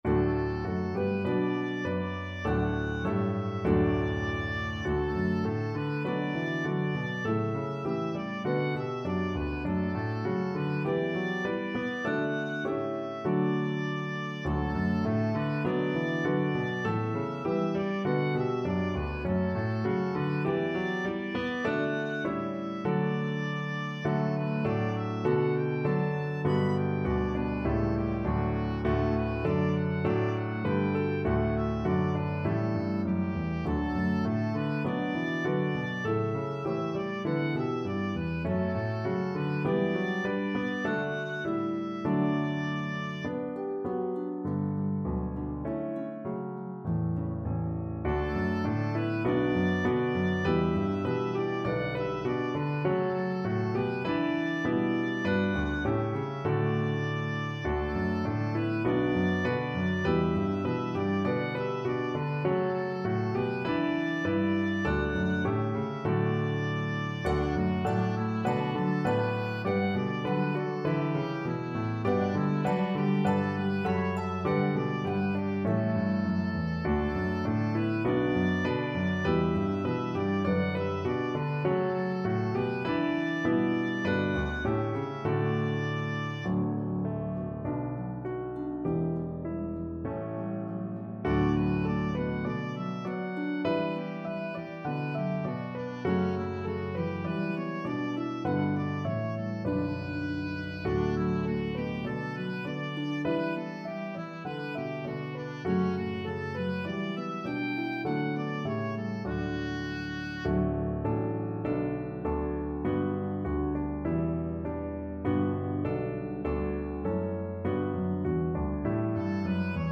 a pentatonic melody